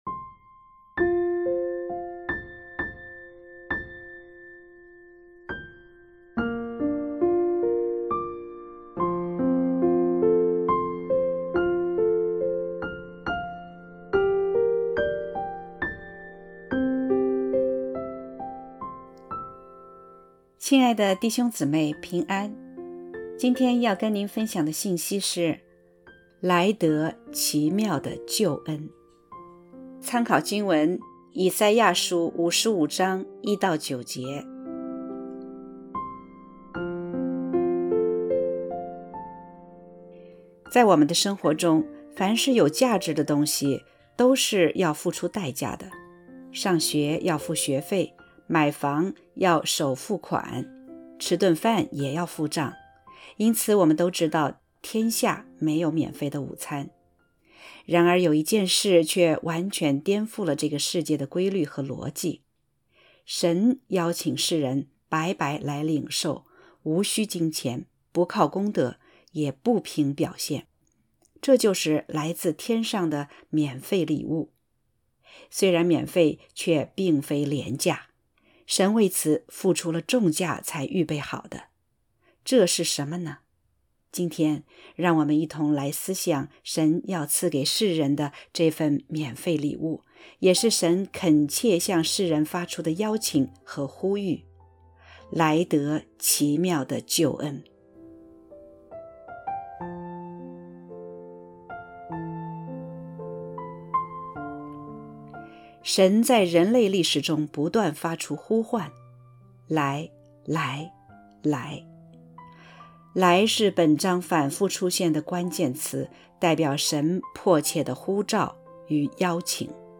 （合成）L来得奇妙救恩.mp3